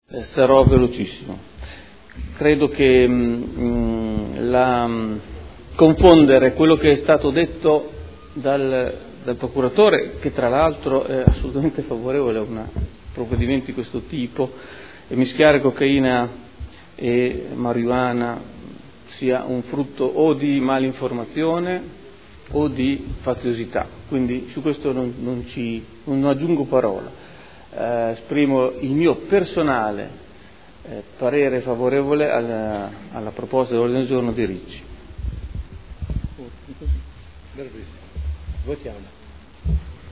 Luigi Alberto Pini — Sito Audio Consiglio Comunale
Seduta del 25/03/2013.